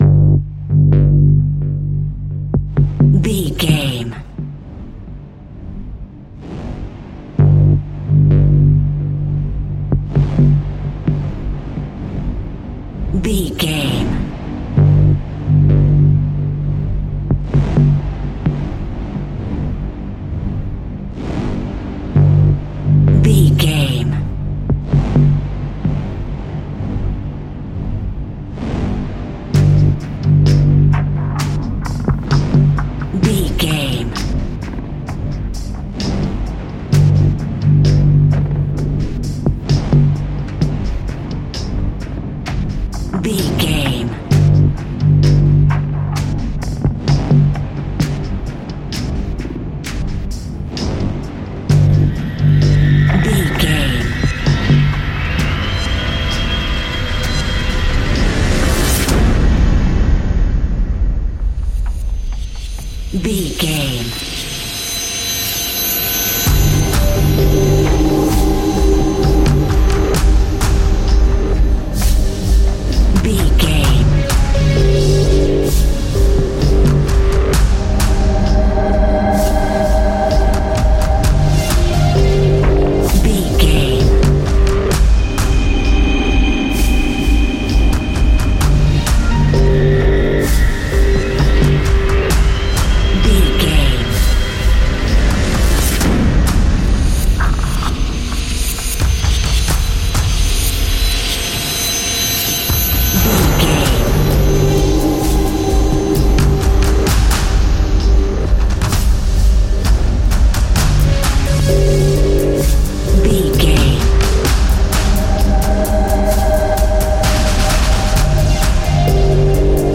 Fast paced
In-crescendo
Ionian/Major
D♭
industrial
dark ambient
EBM
experimental
synths
Krautrock